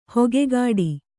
♪ hoge koḍu